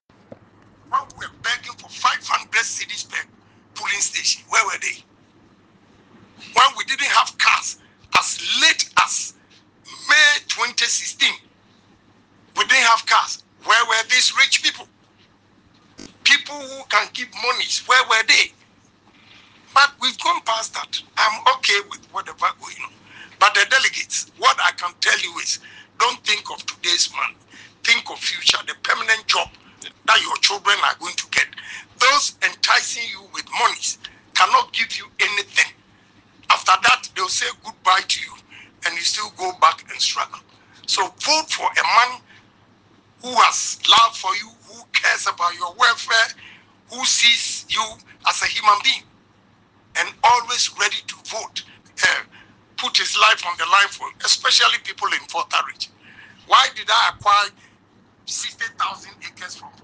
In what many describe as a passionate yet frustrated plea on VOV Radio in the Hohoe Constituency of the Volta Region, the outspoken NPP presidential hopeful called on delegates to reject what he termed “money politics” and instead vote for a leader who truly cares about their welfare.